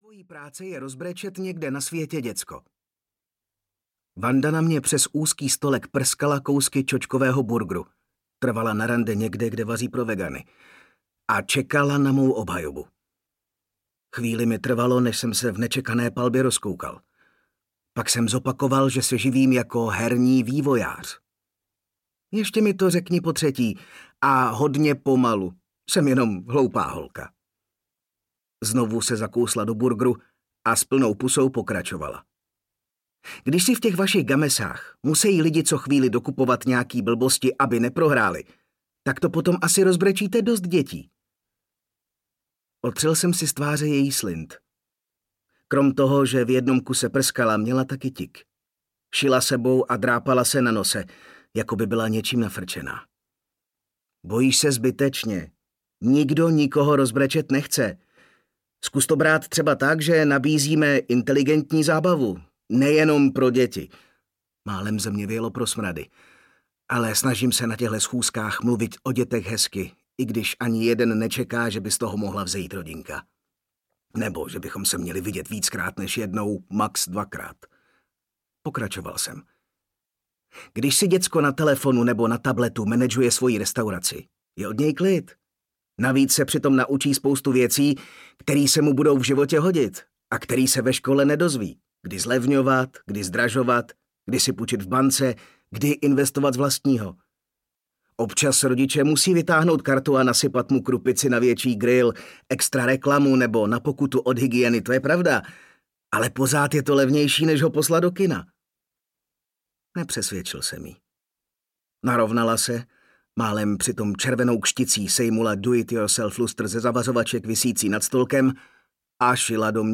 Ukázka z knihy
hry-bez-hranic-audiokniha